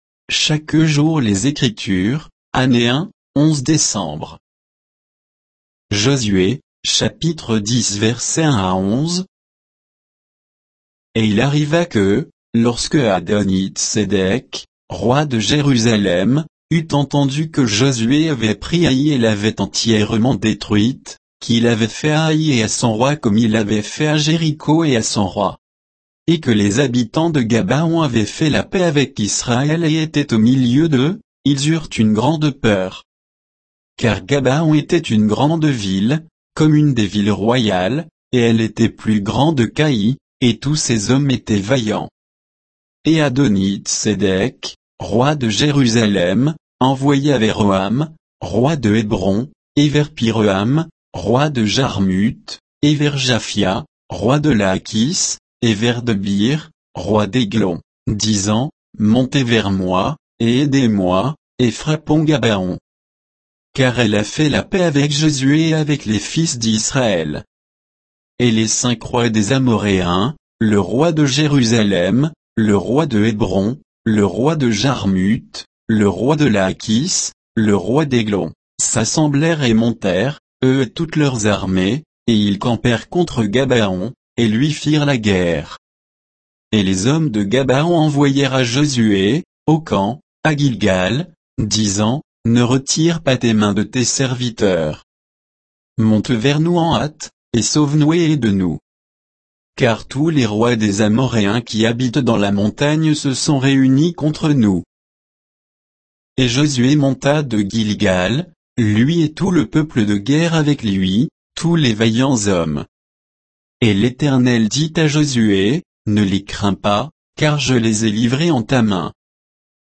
Méditation quoditienne de Chaque jour les Écritures sur Josué 10, 1 à 11